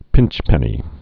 (pĭnchpĕnē)